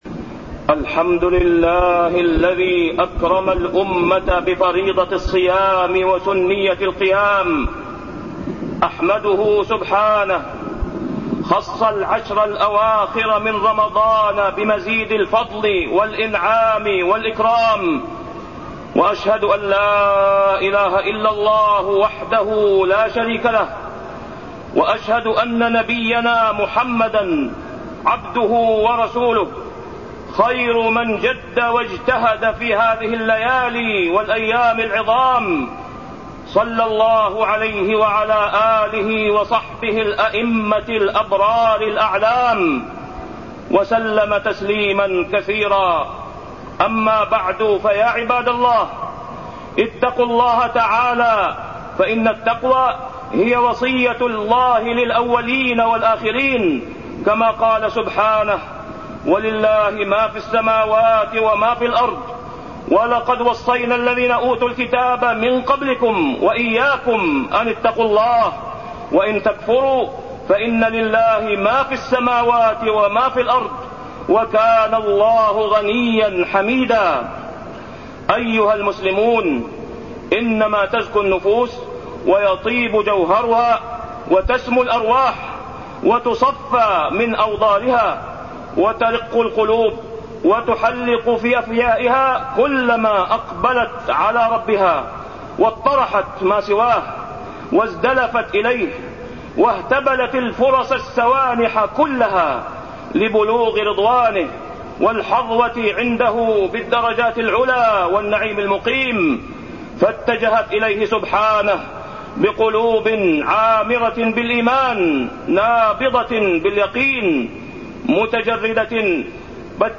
تاريخ النشر ١٩ رمضان ١٤٢١ هـ المكان: المسجد الحرام الشيخ: فضيلة الشيخ د. أسامة بن عبدالله خياط فضيلة الشيخ د. أسامة بن عبدالله خياط الصيام والقيام